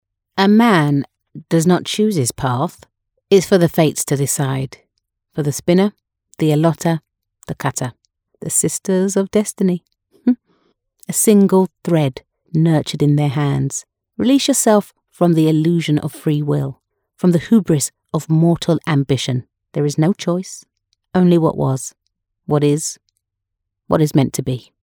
Female
English (British), English (Neutral - Mid Trans Atlantic)
Warm, rich, textured and sincere, able to convey a message clearly to your audience. A voice that inspires confidence, is reassuring, soothing and calm.
Video Games
Sage Character Voice Gaming